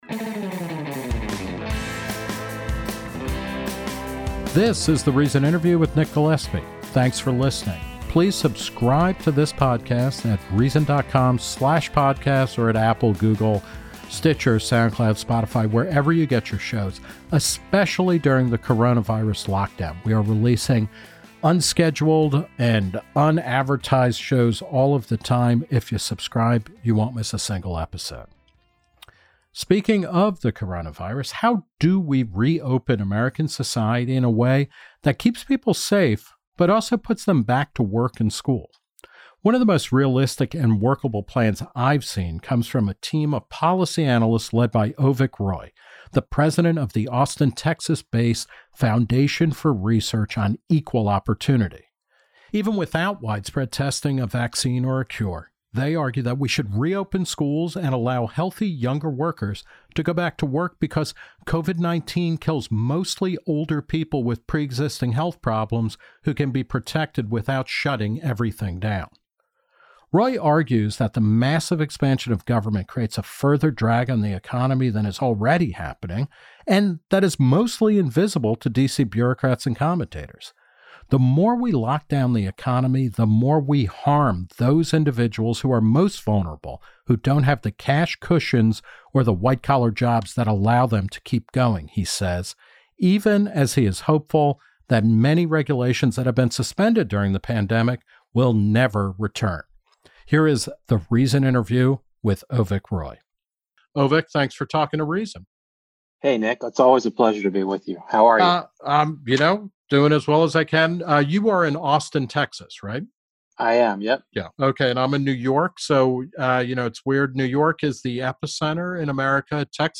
The Reason Interview